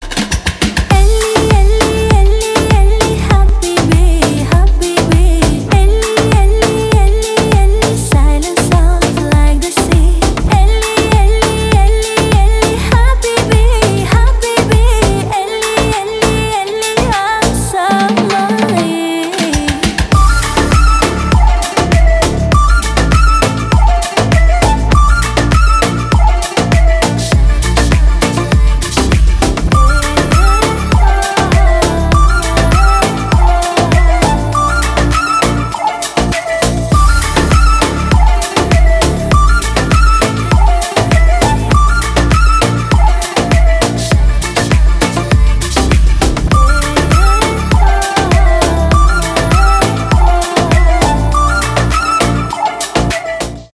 dance pop